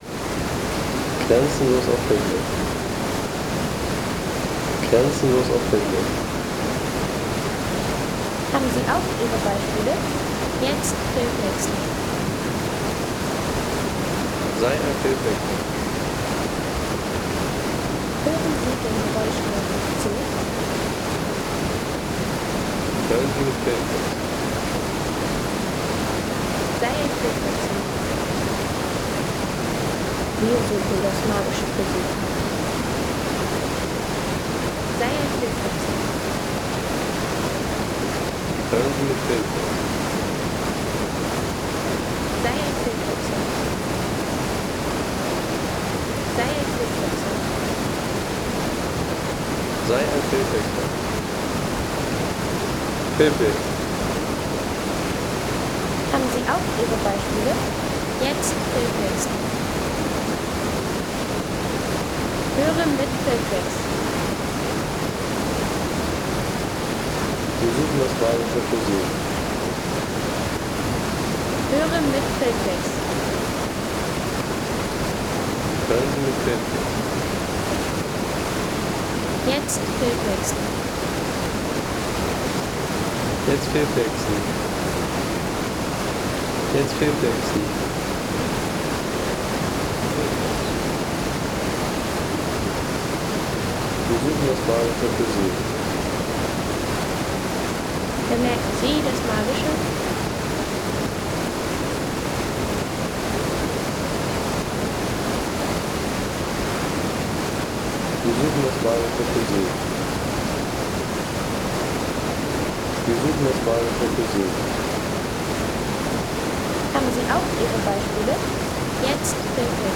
Landschaft - Wasserfälle